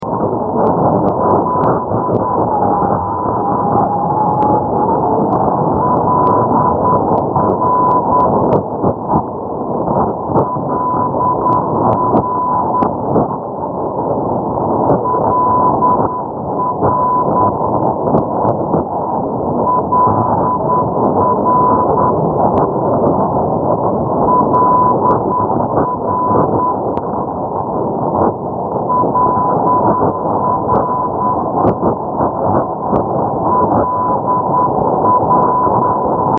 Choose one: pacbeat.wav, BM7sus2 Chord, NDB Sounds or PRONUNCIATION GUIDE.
NDB Sounds